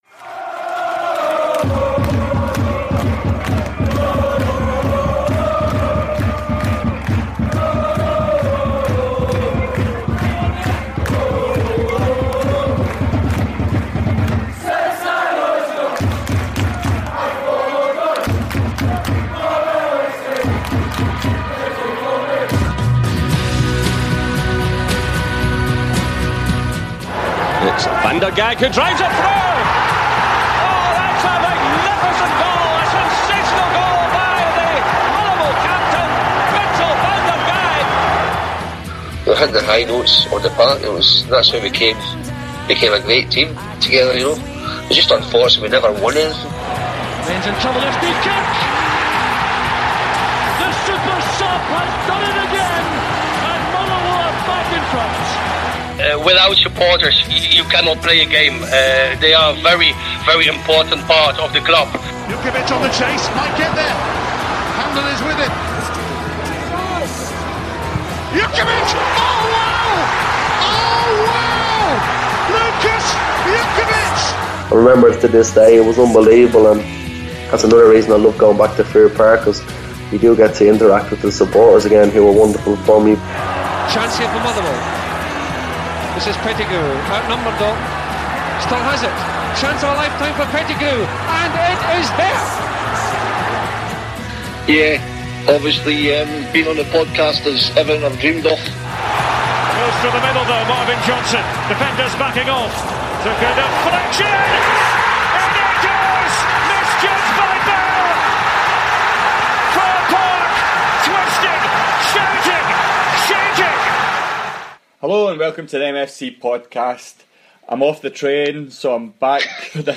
Back to the familiar trio, the lads preview our visit to Ross County this weekend, discuss the progress of the Well Society on the back of their recent Q&A evening as well as debating over our weekly ‘Remember Well’ question.